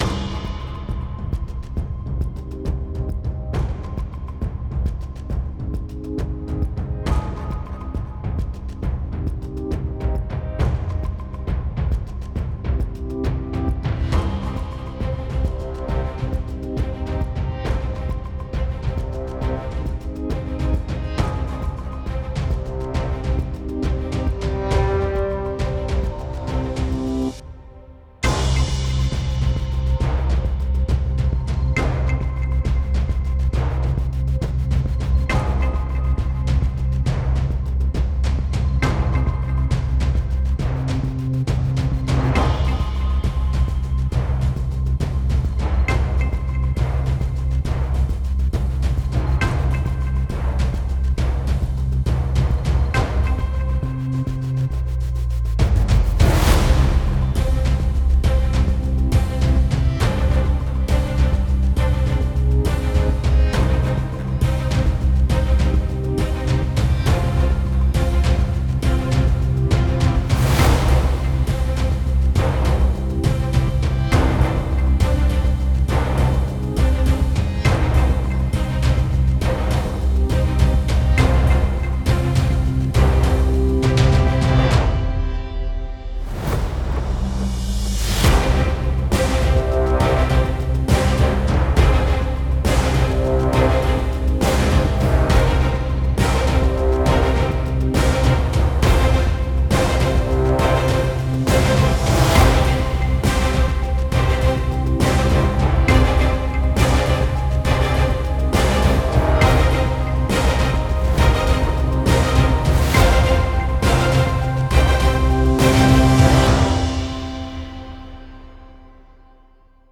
heyecan gerilim aksiyon fon müziği.